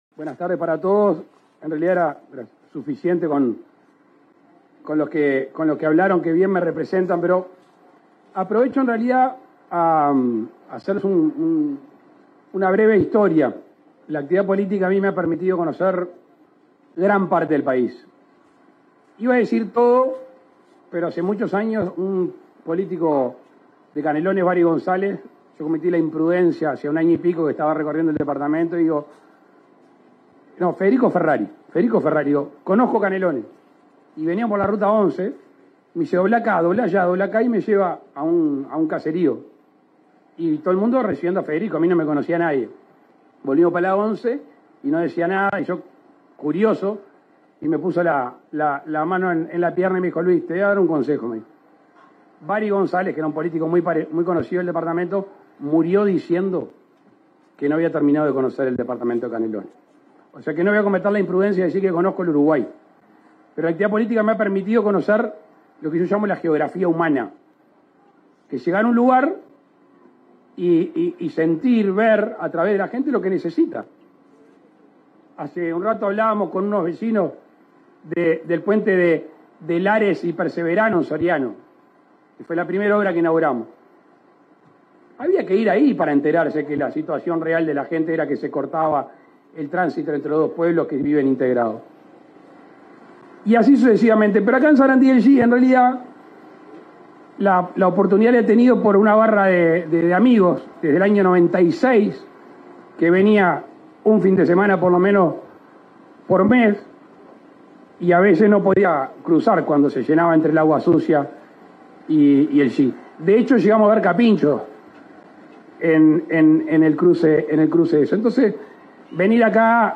Palabras del presidente Lacalle Pou en Sarandí del Yí
En el marco de la inauguración del bypass en ruta 6, kilómetro 201, este 13 de febrero, se expresó el presidente de la República, Luis Lacalle Pou.